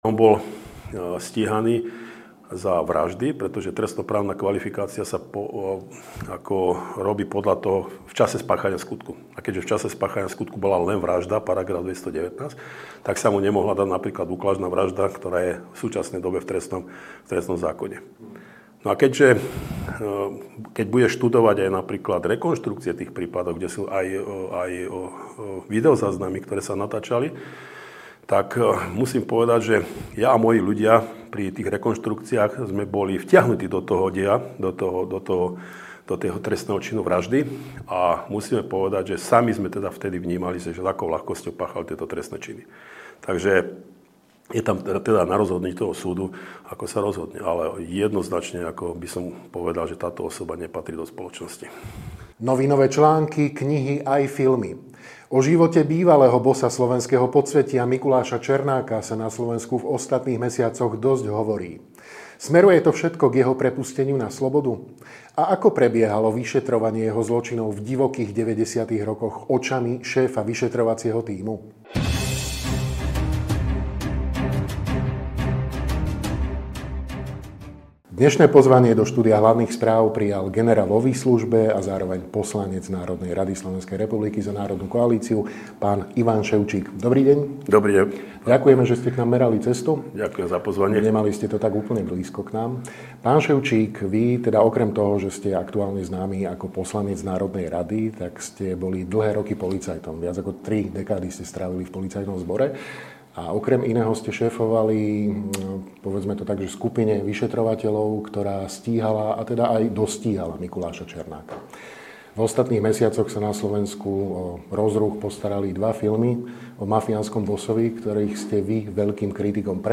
Nielen o divokom období 90-tych rokov na Slovensku, ale aj o súčasnej situácii v Policajnom zbore aj na politickej scéne, sme sa rozprávali s generálom v. v. a poslancom NR SR, Ing. Ivanom Ševčíkom.